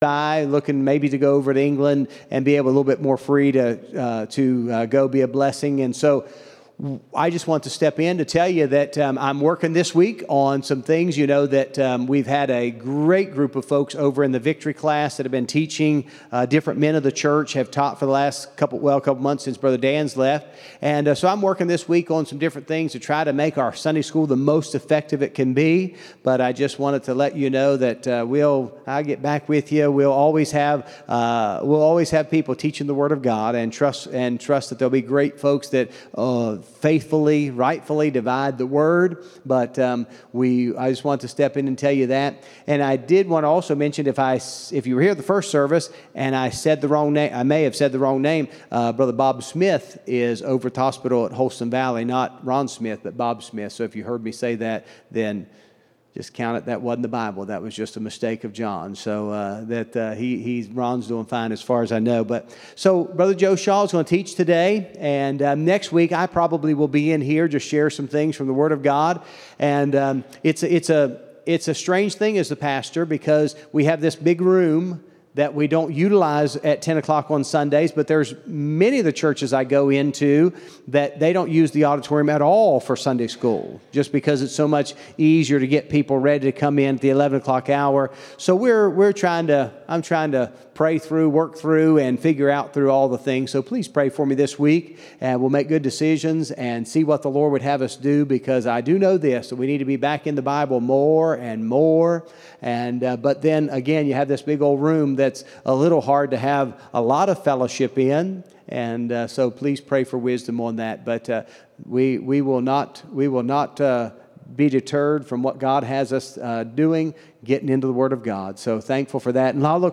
04-14-24 Sunday School | Buffalo Ridge Baptist Church